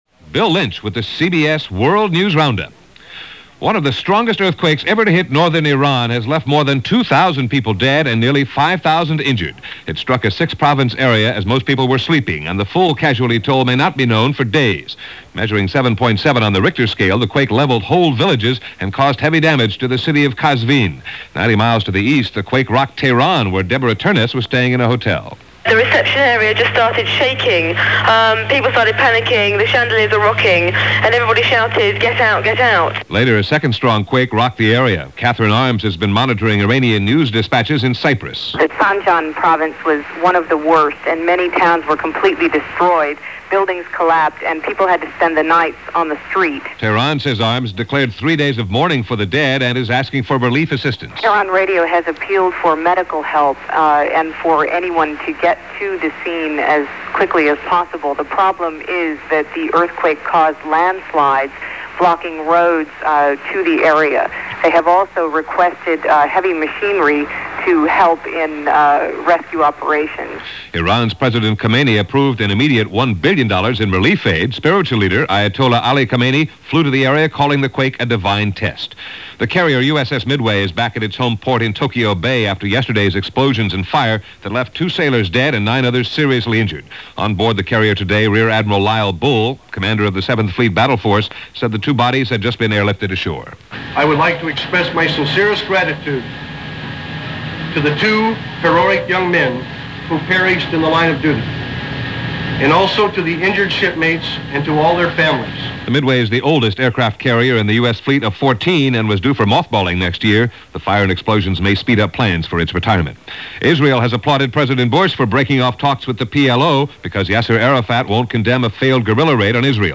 CBS World News Roundup – June 20, 1990 – Gordon Skene Sound Collection